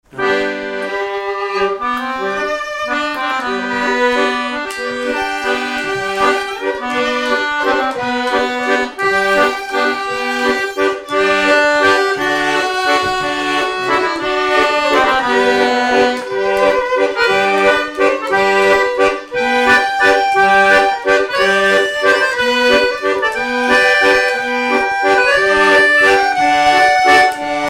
danse : valse
violon
Pièce musicale inédite